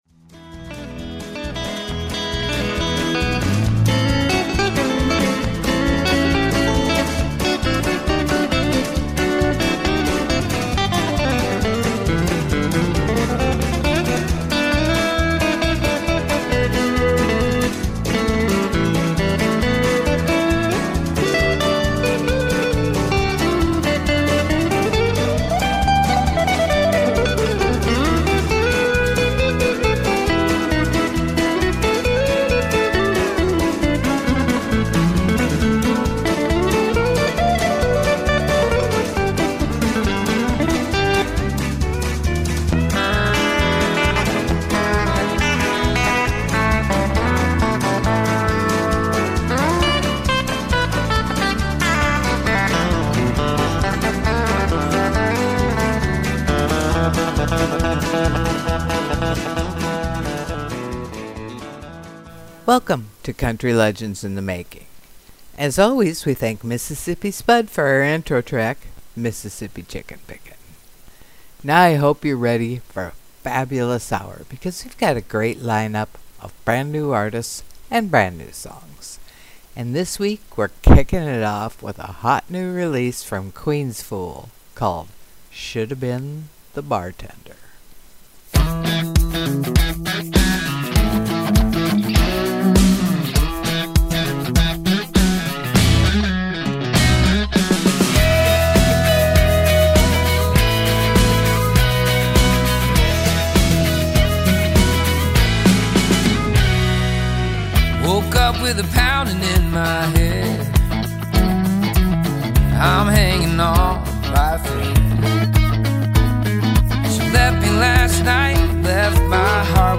© Triplestrand Productions 2004 - 2025 Tsp Country Radio's flagship radio show which started back in 2004 not thinking that from such humble beginnings just how quickly it's popularity would spread.
the show features the very best in emerging Independent Country Artists only,offering a vast range of music within the complete spectrum that is Country Music Today with fresh new and original songs often making their Radio Debut.